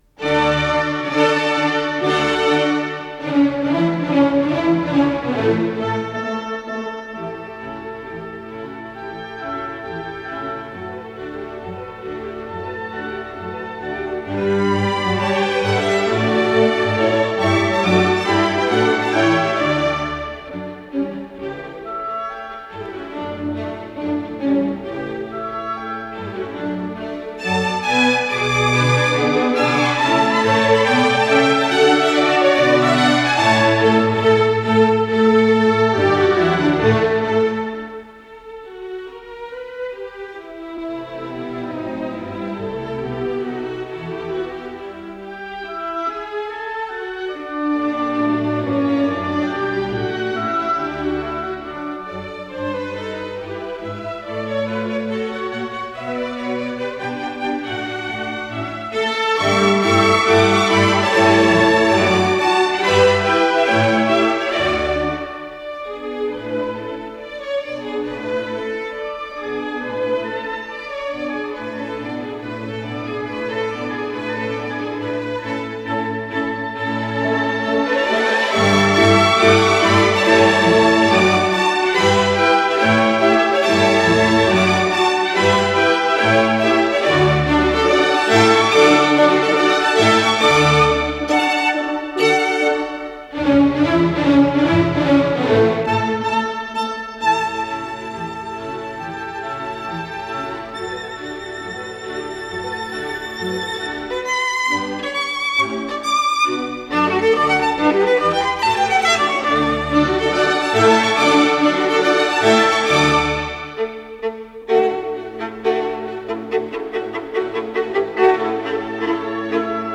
Исполнитель: Марк Лубоцкий - скрипка
Название передачи Концерт №7 для скрипки и камерного оркестра Подзаголовок Соч. К-271 /а/, ре мажор Код ДКС-11508 Фонд Норильская студия телевидения (ГДРЗ) Редакция Музыкальная Общее звучание 00:29:53 Дата записи 16.06.1971 Дата переписи 04.01.1972 Дата добавления 29.04.2025 Прослушать